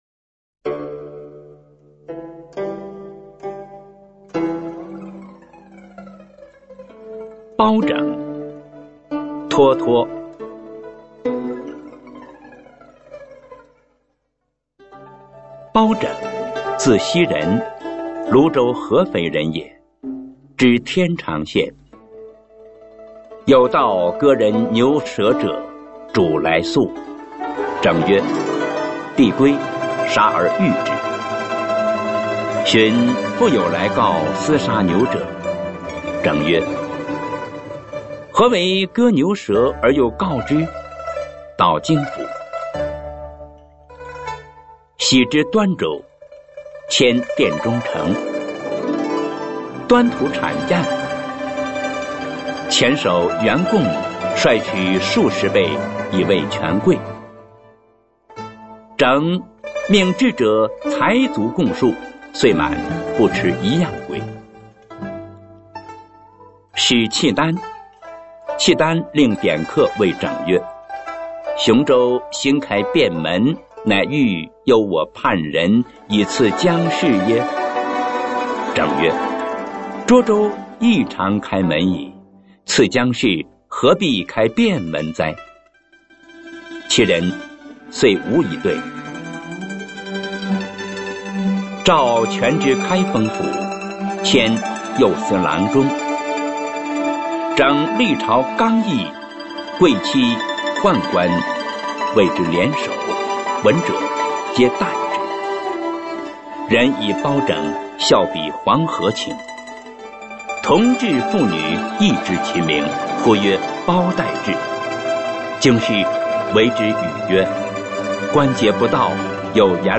脱脱《包拯》原文与译文（含Mp3朗读）